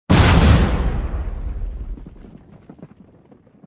youhit4.ogg